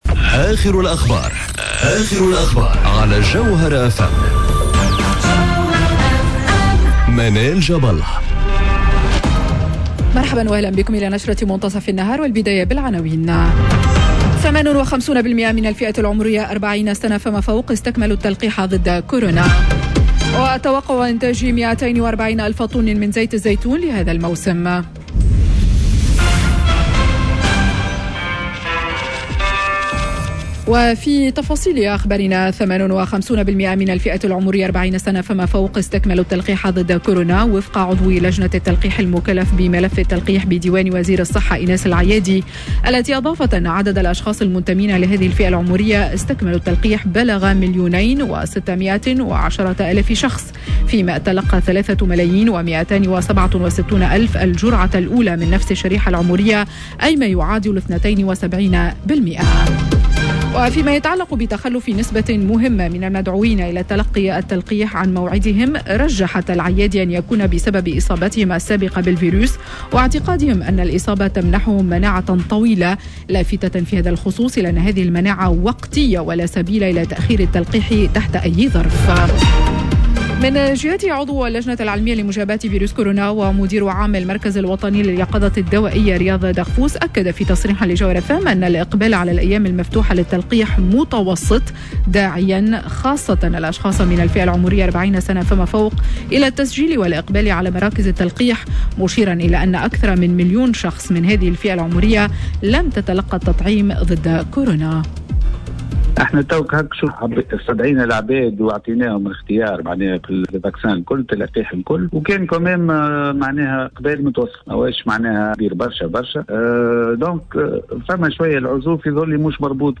نشرة أخبار منتصف النهار ليوم الإثنين 04 أكتوبر 2021